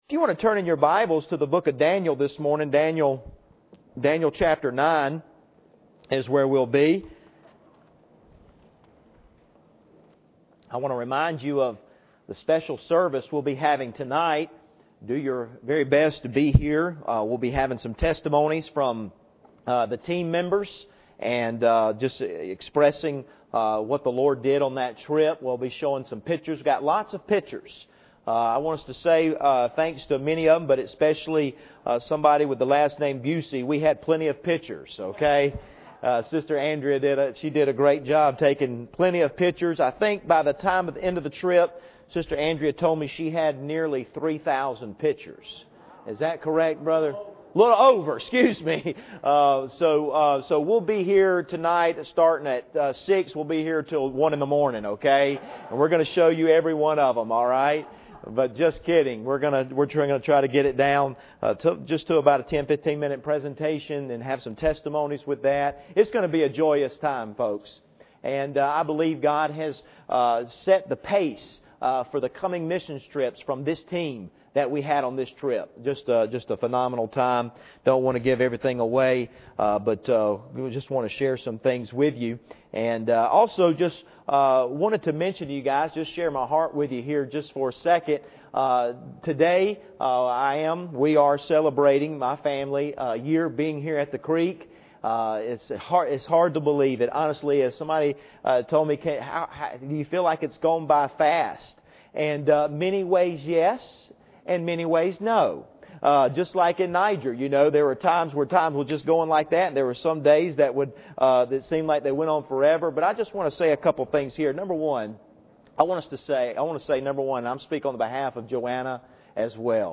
Passage: Daniel 9:1-6 Service Type: Sunday Morning